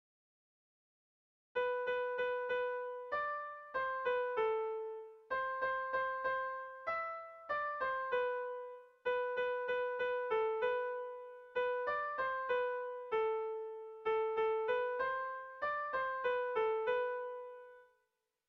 Kopla handia